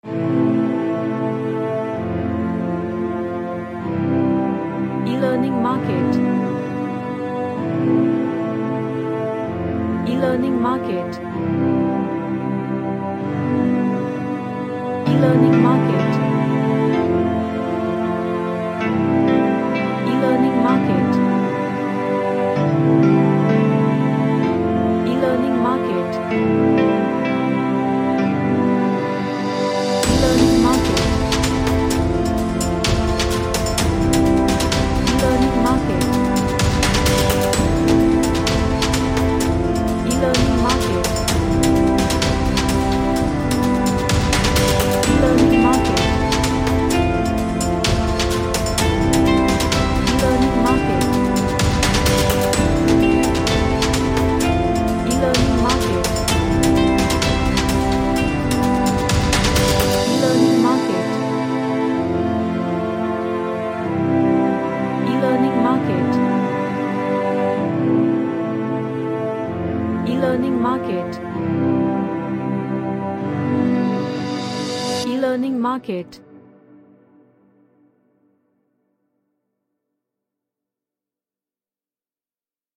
A Cinematic track with electric guitar melody.
Energetic